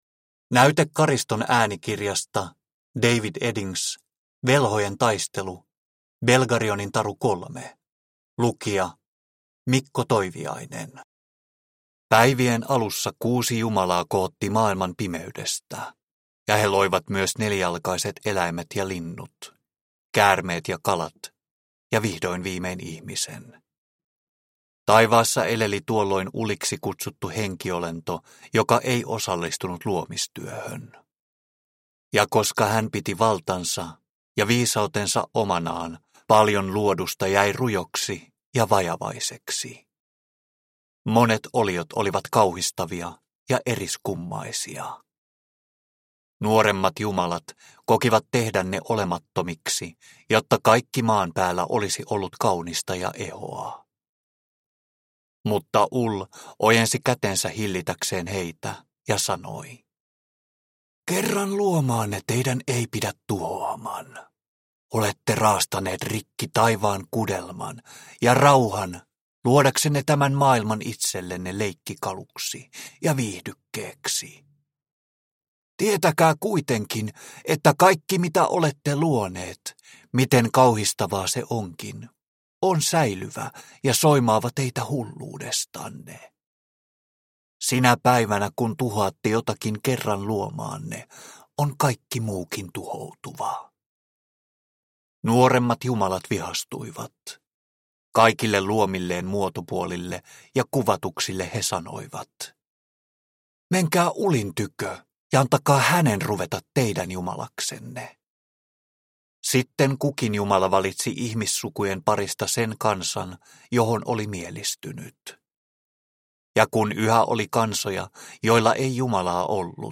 Velhojen taistelu - Belgarionin taru 3 – Ljudbok – Laddas ner